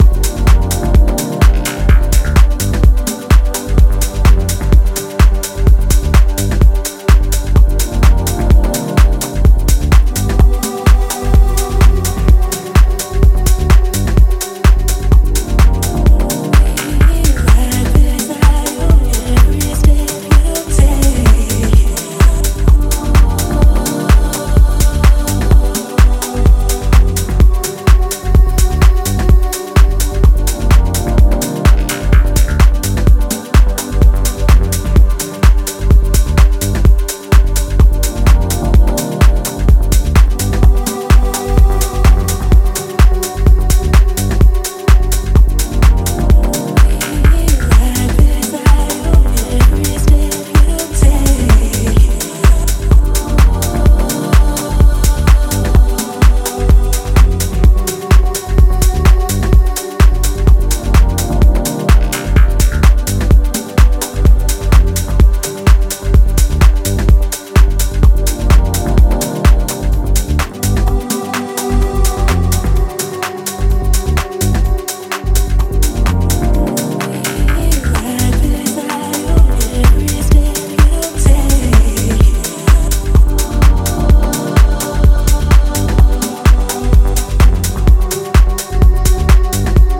ジャンル(スタイル) DEEP HOUSE / HOUSE / TECH HOUSE